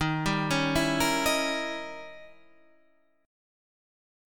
D# 7th Flat 9th